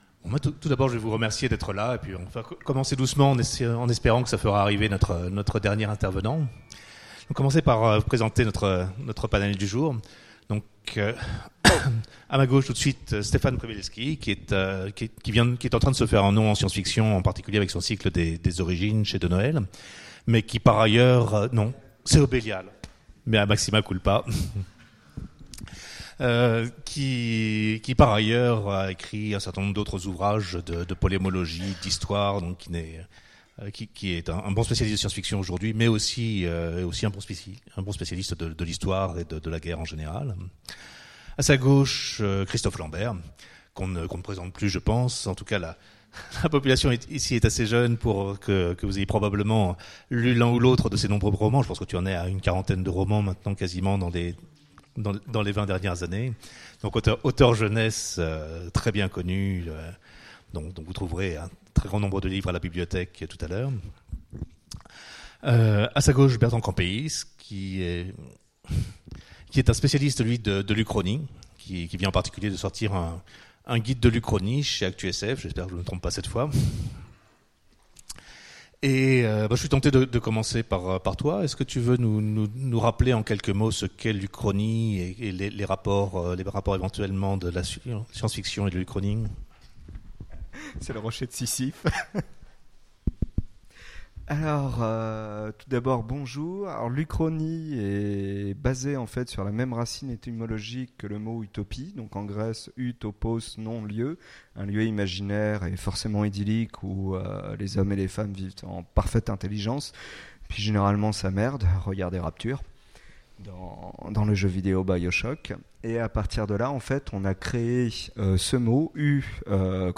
Utopiales 2017 : Conférence Regagner la guerre
Conférence